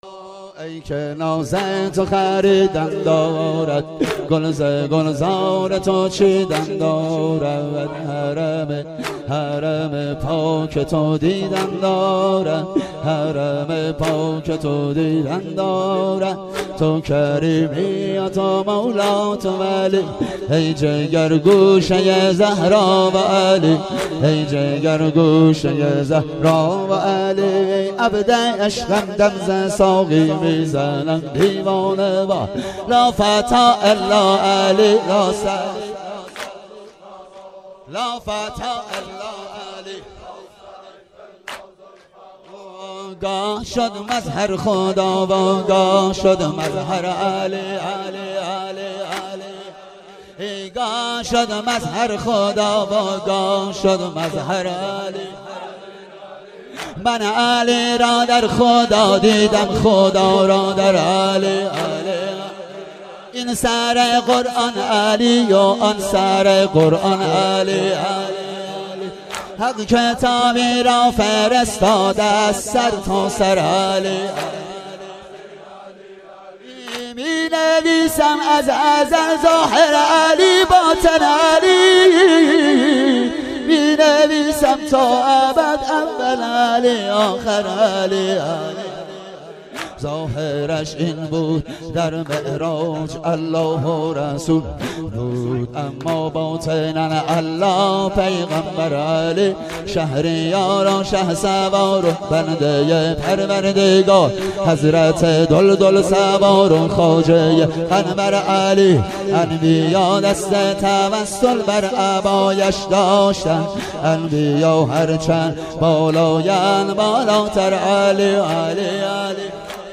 فاطمیه
واحد مداحی